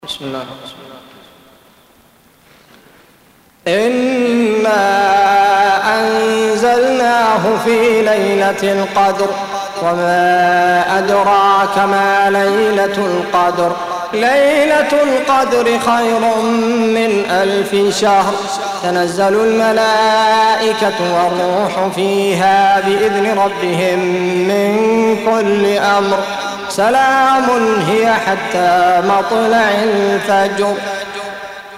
Audio Quran Tarteel Recitation
حفص عن عاصم Hafs for Assem
Surah Sequence تتابع السورة Download Surah حمّل السورة Reciting Murattalah Audio for 97. Surah Al-Qadr سورة القدر N.B *Surah Includes Al-Basmalah Reciters Sequents تتابع التلاوات Reciters Repeats تكرار التلاوات